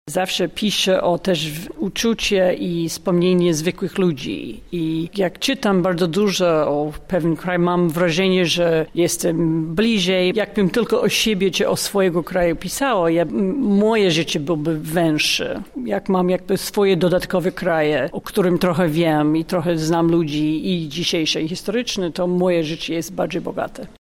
Anne Applebaum odwiedziła Lublin. Dziennikarka opowiadała o swojej nowej książce ,,Czerwony głód” oraz o Ukrainie.
• mówi Anne Applebaum, autorka książki ,,Czerwony Głód”.